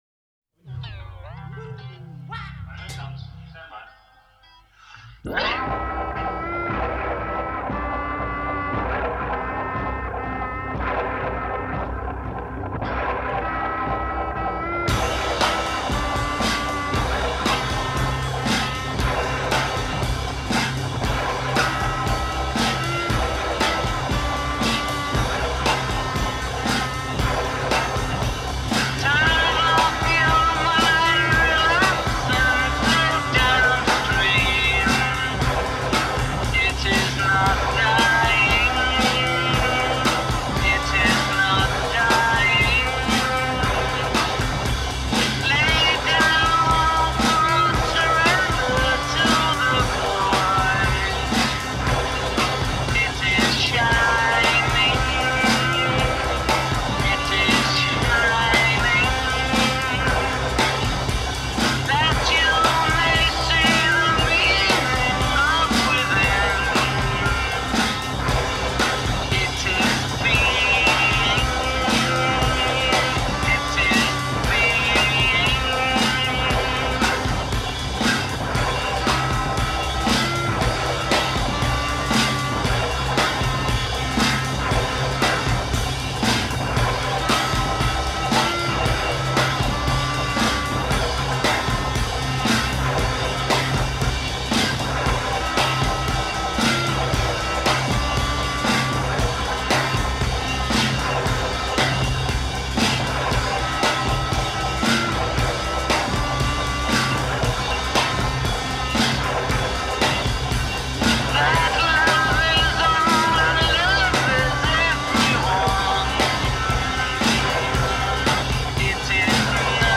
Here’s the druggy, fuggy first take: